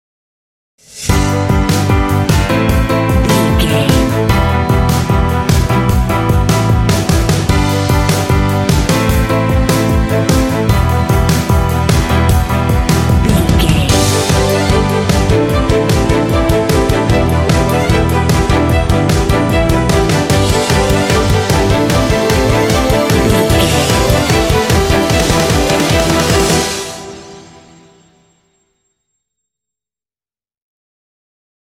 Uplifting
Aeolian/Minor
proud
confident
bright
hopeful
strings
piano
drums
indie
alternative rock